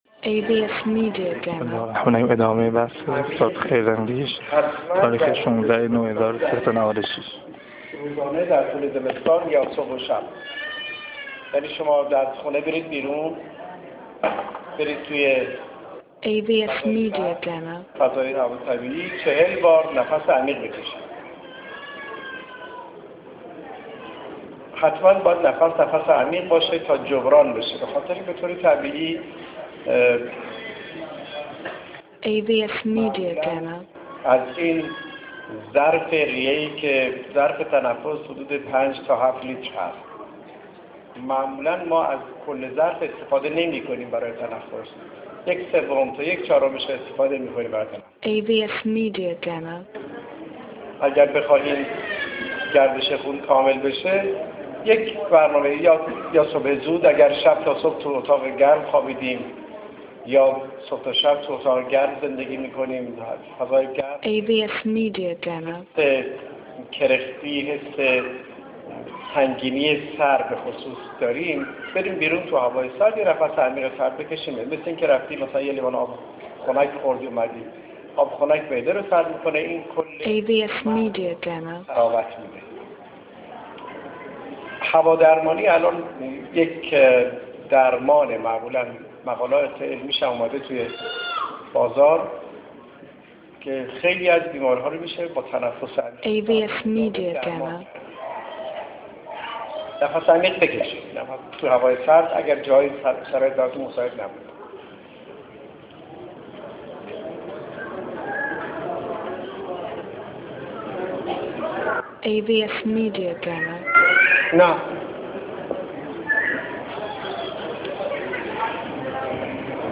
مسجدعبداللهی